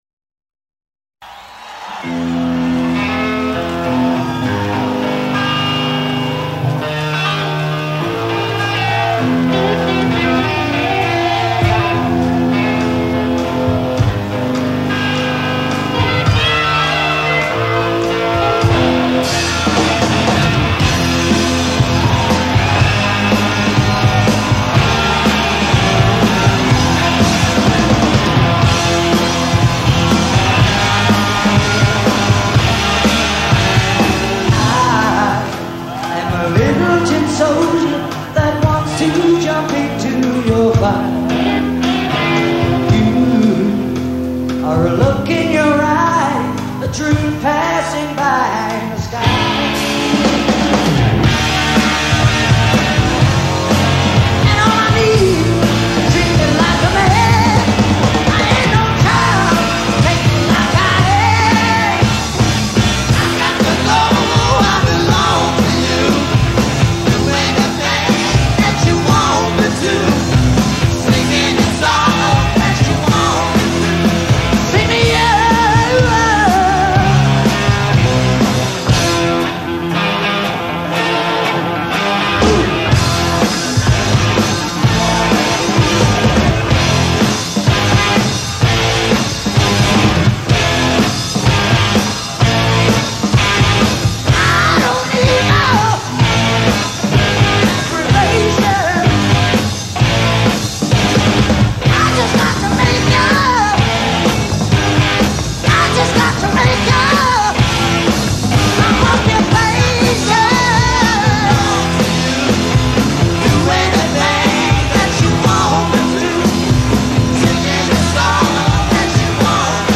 as the lead singer
performed live in 1981